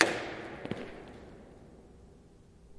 描述：07.11.09: between 13.00 and 15.00, the KAMIEŃ STONE 2009 Stone Industry Fair(from 4th to 7th November) in Poznań/Poland. Eastern Hall in MTP on Głogowska street: the general ambience of the fair hall
标签： crowd hall mtp noise poland poznan steps stonefair voices
声道立体声